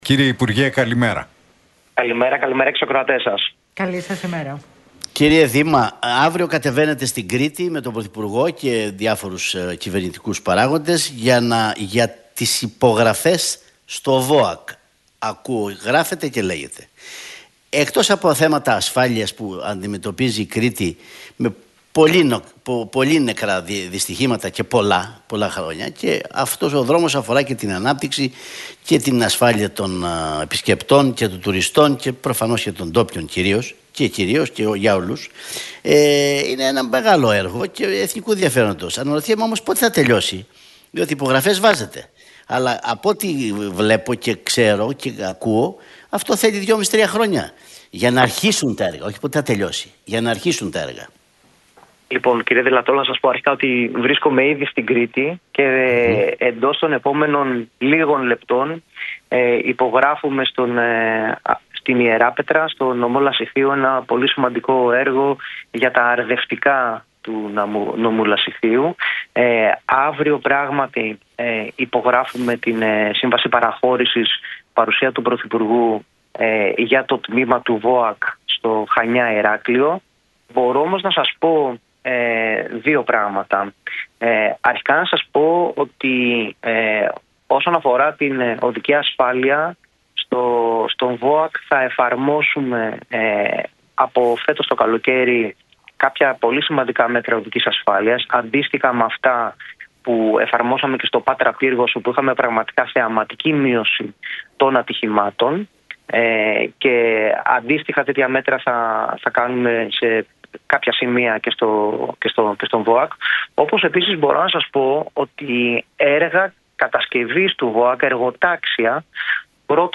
Χρίστος Δήμας στον Realfm 97,8: Αναλογικά διόδια στο τμήμα του αυτοκινητόδρομου Πάτρα - Πύργος - Θα πρέπει να επεκταθούν στο σύνολο των αυτοκινητοδρόμων
Για τον ελληνικό σιδηρόδρομο, τον ΒΟΑΚ, τα έργα στο μετρό και τους αυτοκινητόδρομους μίλησε ο υπουργός Υποδομών και Μεταφορών, Χρίστος Δήμας στους Νίκο Χατζηνικολάου